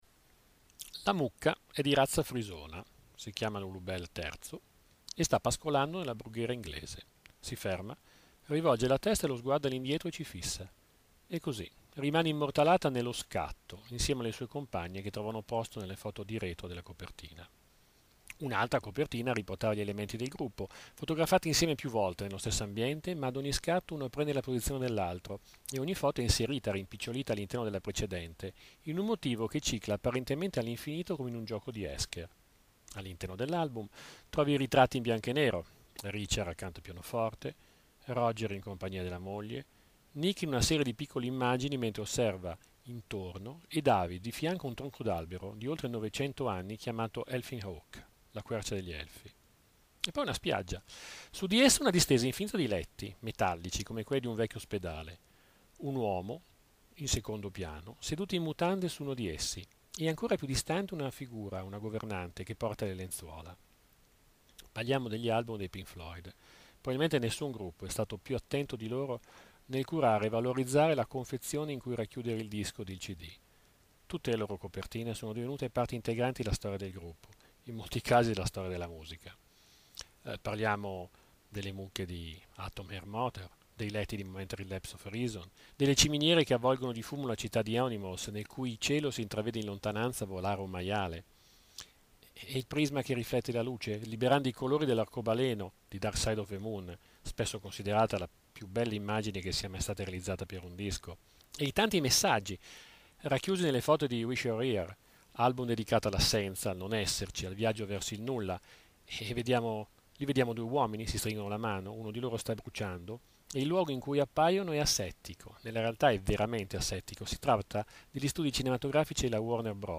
Puntate della rubrica radiofonica l'Angolo di Orso Curioso trasmessa da Web Pieve Radio